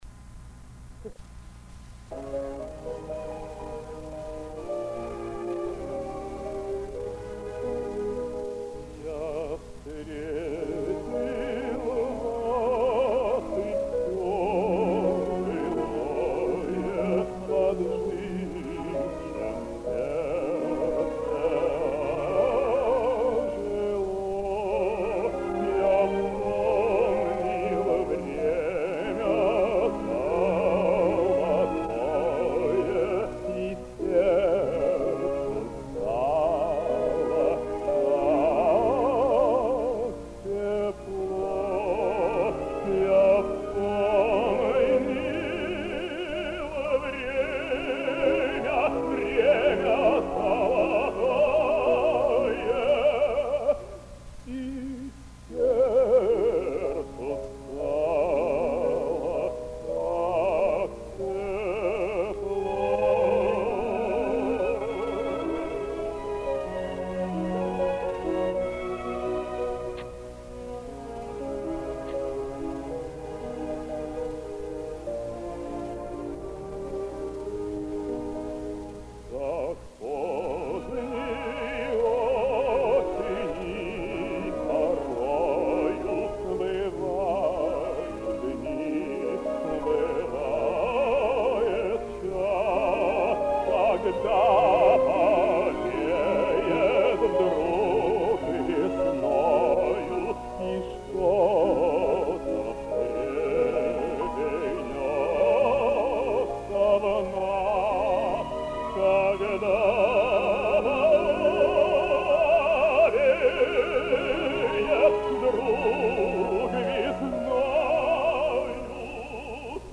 Романсы на стихи Ф.И. Тютчева: